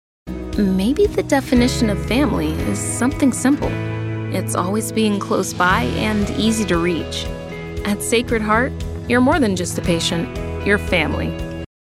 Commercial & problem Corporate Work
Sacred Heart — Warm, Empathetic, Conversational
Commercial-Sacred-Heart.mp3